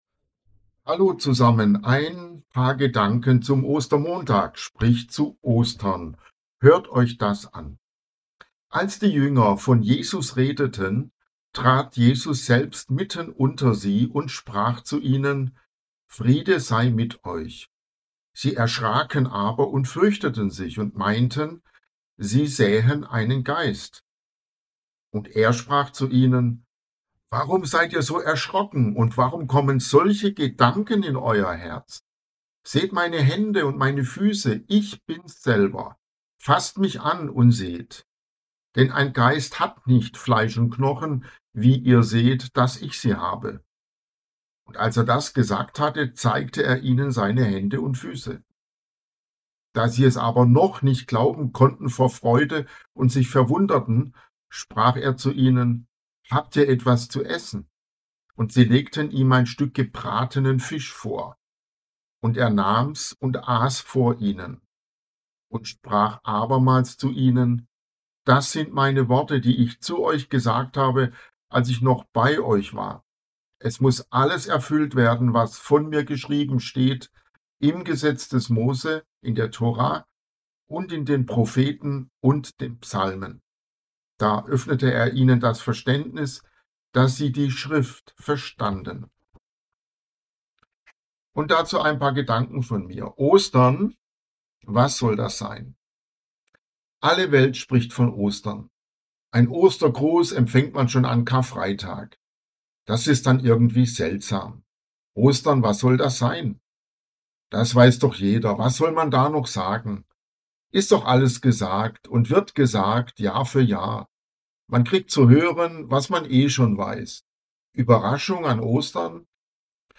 Überraschung - eine Osterpredigt!